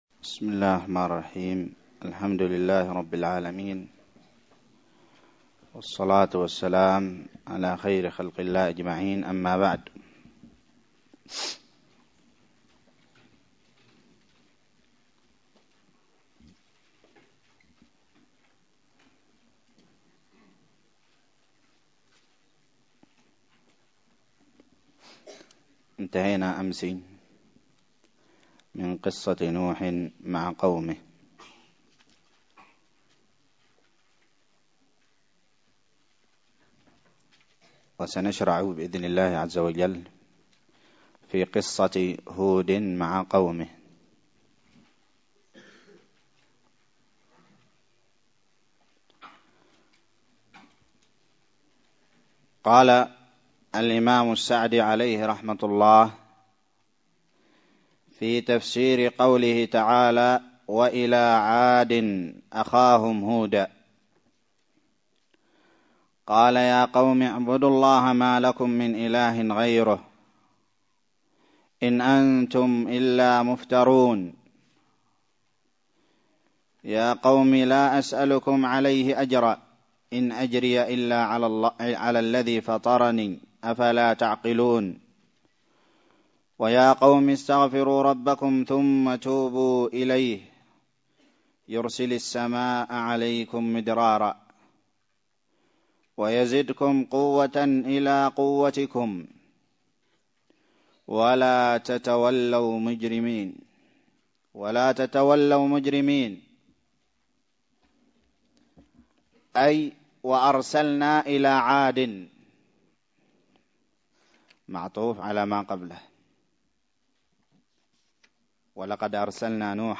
الدرس السادس عشر من تفسير سورة هود
ألقيت بدار الحديث السلفية للعلوم الشرعية بالضالع